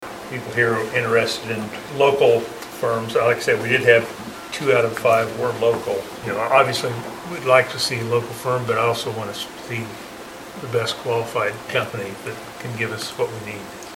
Commission Chair Greg McKinley asked if there was any special consideration given to the local firms that applied.